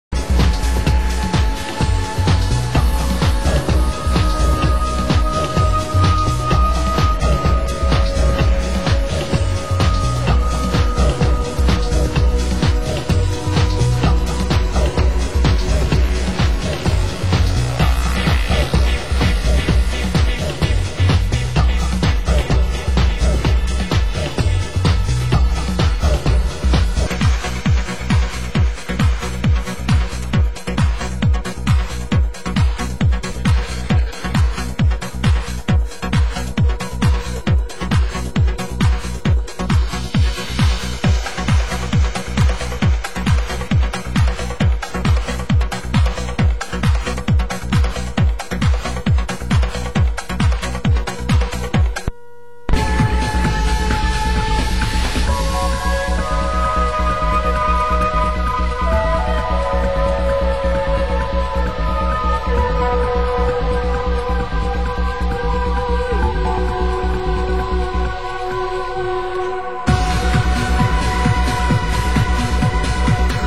Genre: Progressive